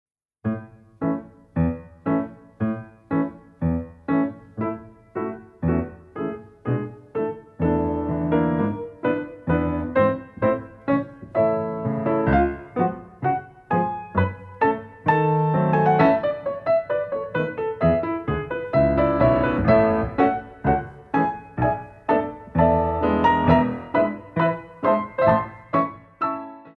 Echappés